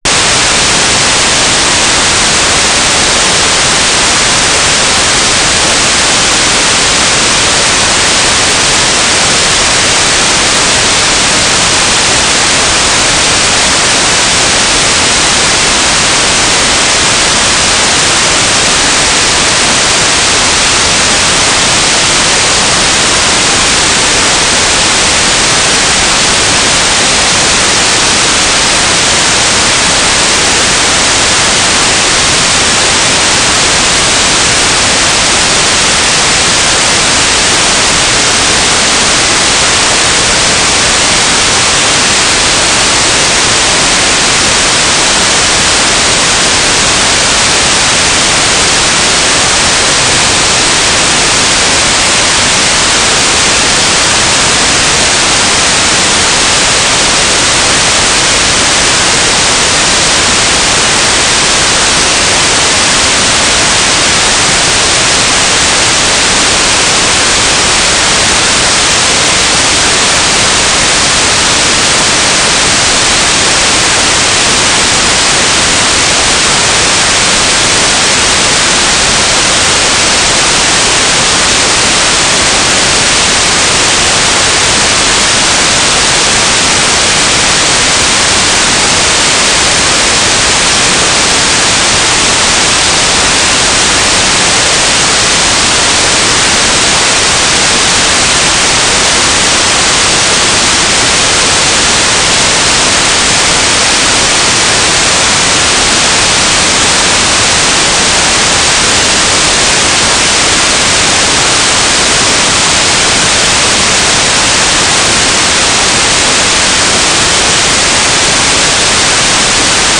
"transmitter_description": "Mode U - GMSK9k6",